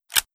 ShotgunLoad_1.wav